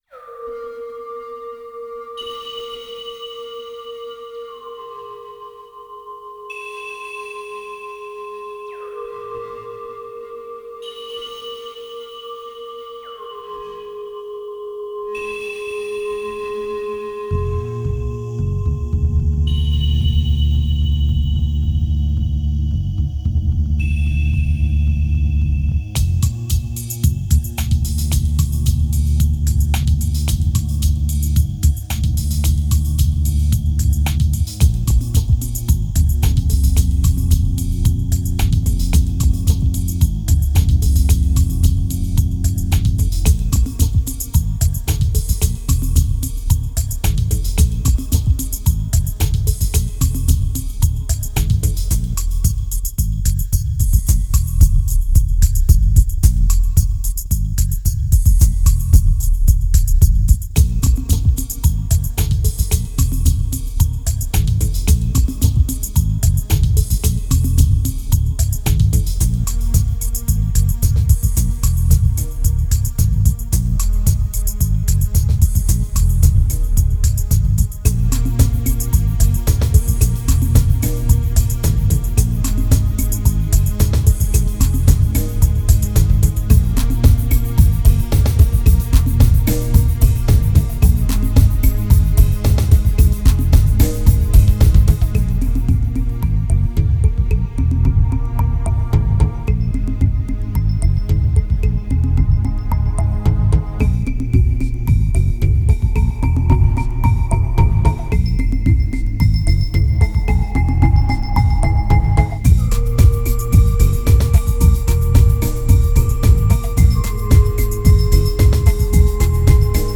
2297📈 - -2%🤔 - 111BPM🔊 - 2009-12-12📅 - -192🌟